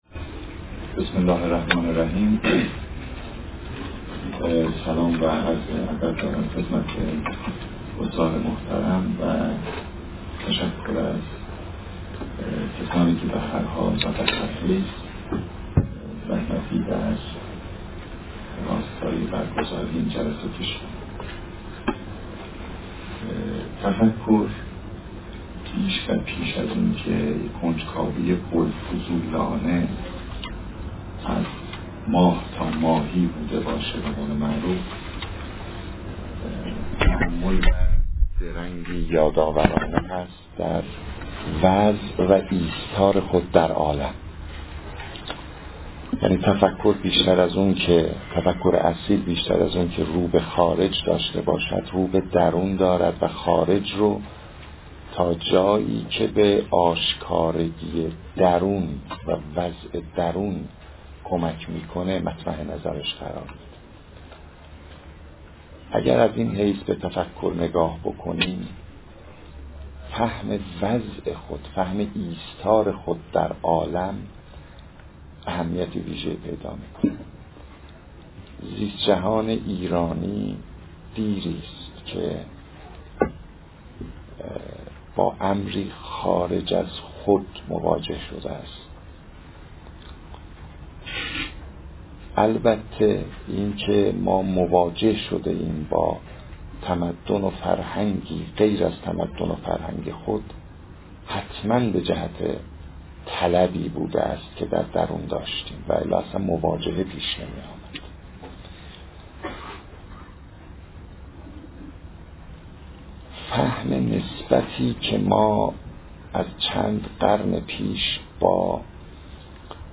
مناظره
در سالن اجتماعات پژوهشگاه علوم انسانی و مطالعات فرهنگی برگزار شد.